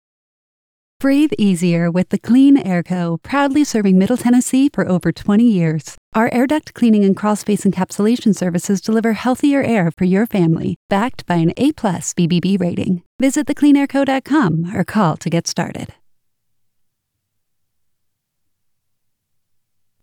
My voice has been described as warm, intelligent, & effortlessly cool - perfect for projects that demand professionalism & natural delivery. I'm known for pairing approachable expertise with a comforting, friendly & competent tone.